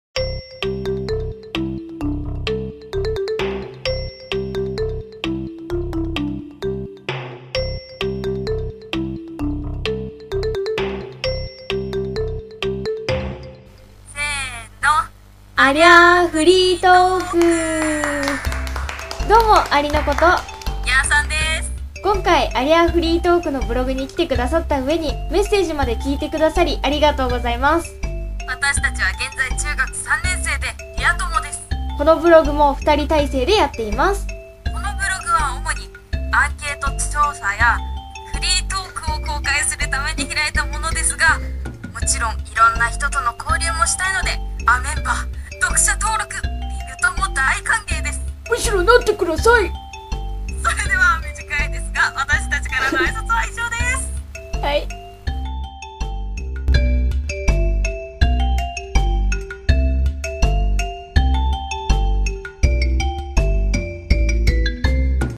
あいさつ
音割れ、滑舌、ノイズ、棒読み、音量　に注意してください←